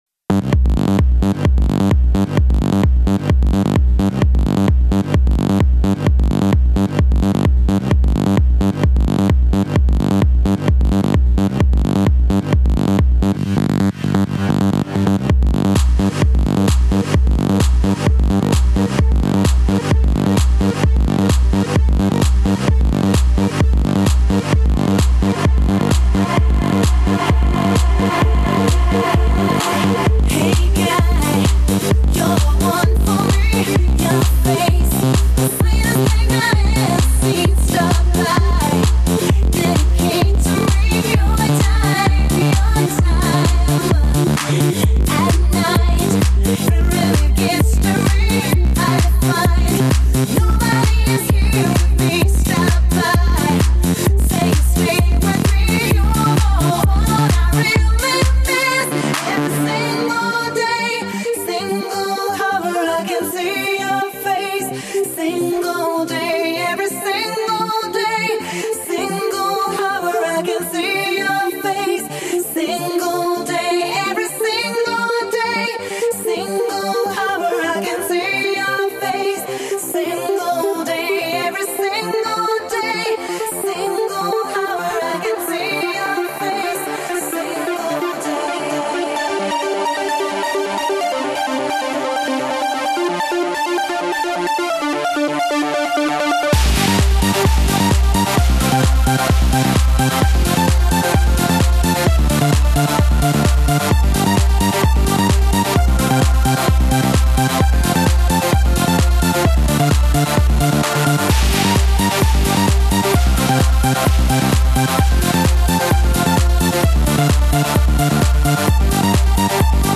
Dance music